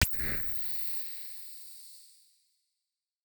SFX - tv turning on.wav